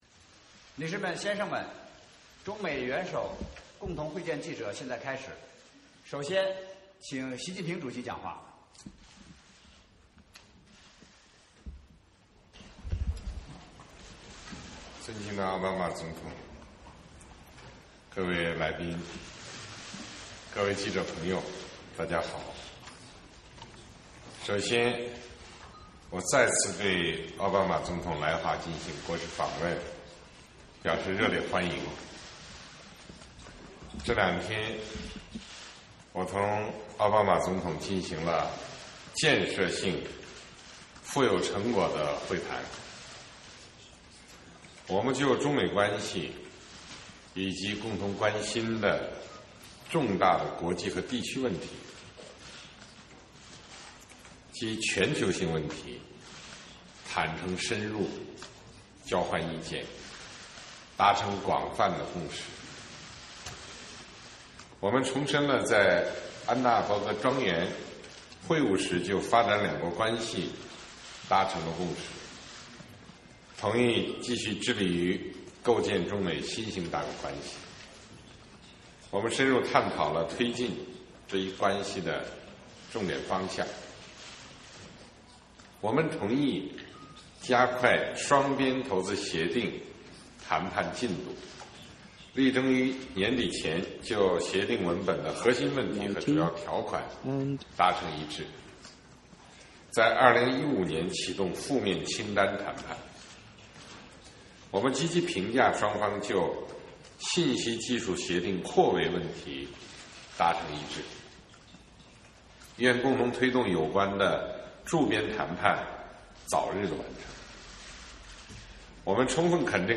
美国总统奥巴马和中国国家主席习近平举行联合记者招待会